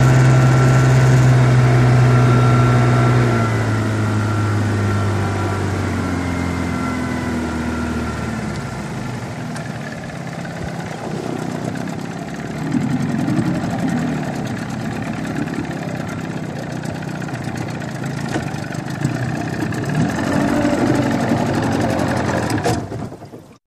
20 hp Johnson Boat Pull Up Shut Off, On Board